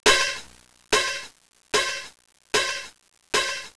Cymbal
Cymbal.wav